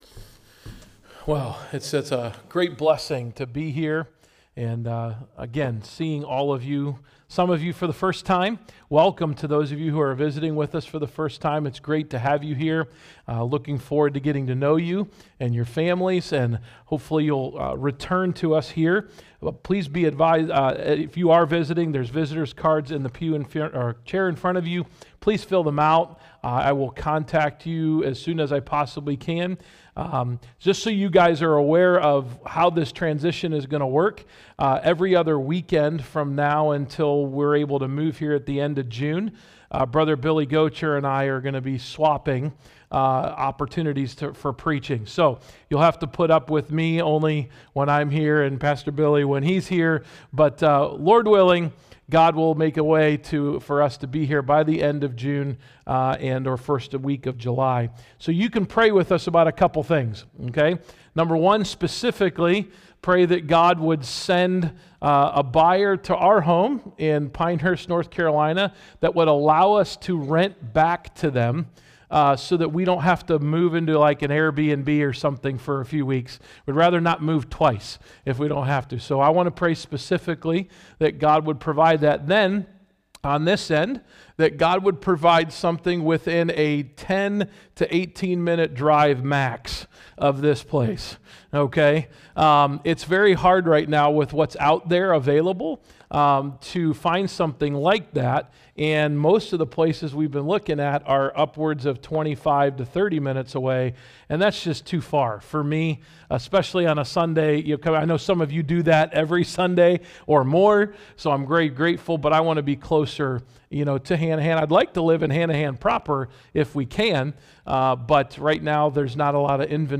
March-1-AM-Service.mp3